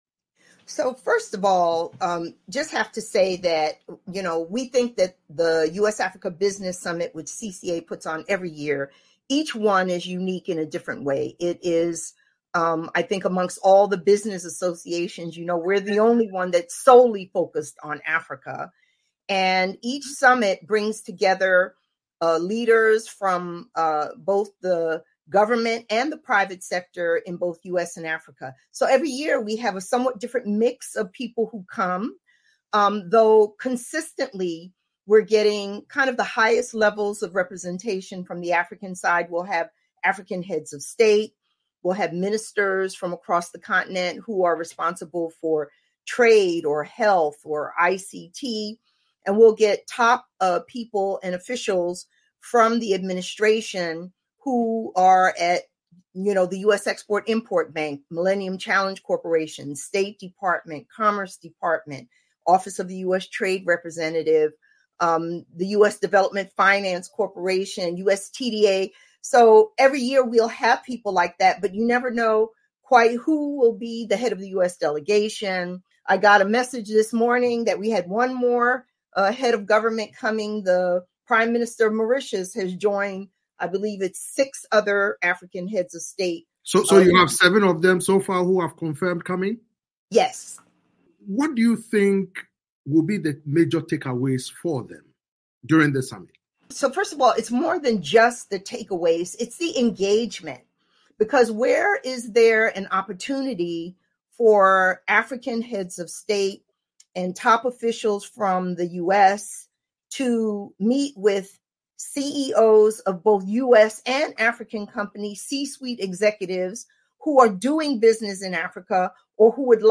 This is the first of a two-part conversation.